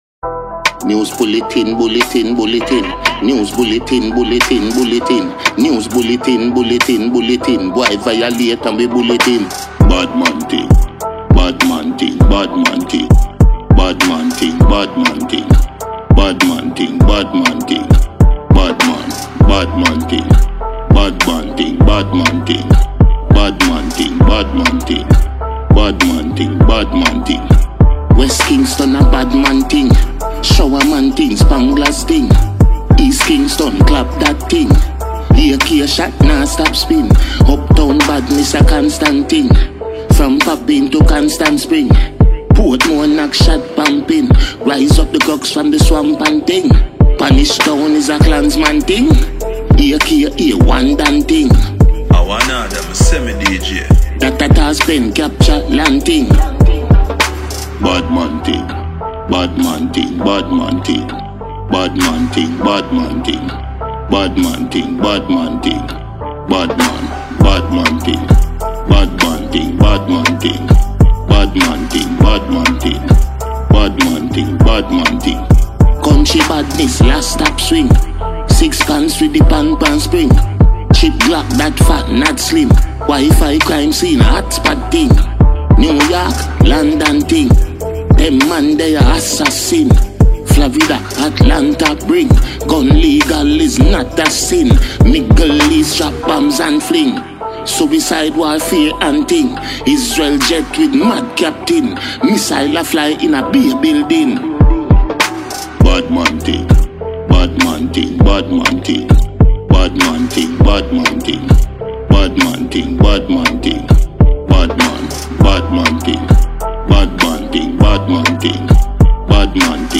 Enjoy and download free mp3 dancehall song